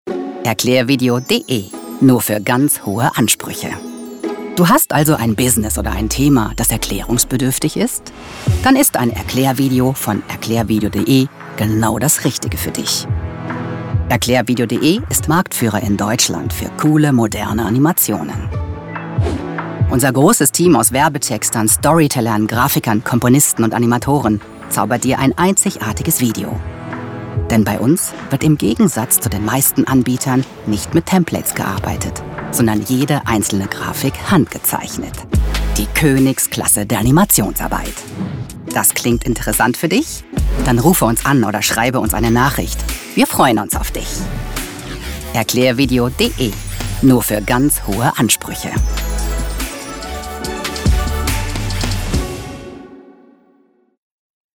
Charakterstimme, erregt Aufmerksamkeit, samtig-tief, einfĂŒhlsam, innovativ, glaubhaft, hohe Wiedererkennung, TV-Werbung, Audioguide, Doku / Off, E-Learning, Feature, Spiele, Funkspot, Imagefilm, Meditation, Nachrichten, ErklĂ€rfilm, Promotion, Sachtext, Trailer, Station-Voice, VoiceOver, Tutorials
Sprechprobe: eLearning (Muttersprache):
Character voice, attracts attention, velvety-deep, sensitive, innovative, credible, high recognition, TV commercial, audio guide, documentary / off-camera, e-learning, feature, games, radio spot, image film, meditation, news, explanatory film, promotion, factual text, trailer, station voice, voiceover, tutorials